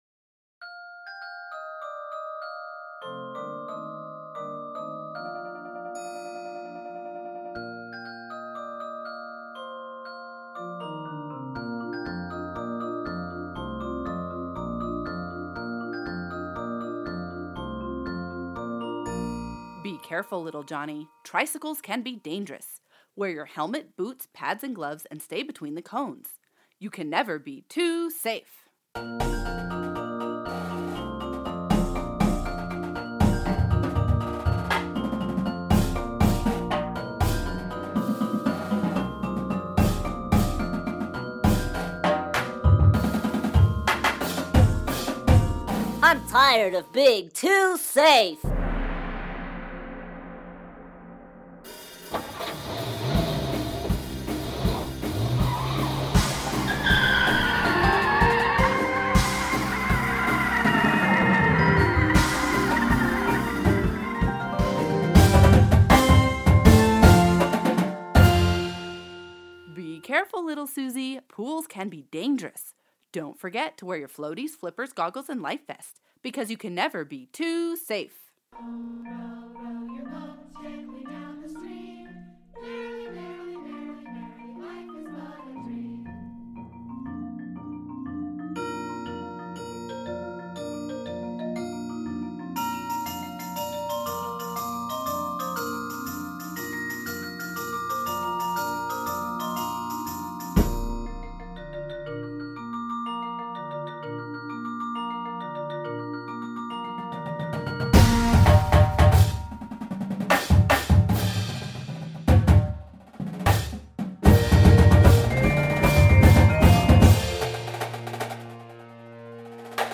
comedic Indoor Percussion Show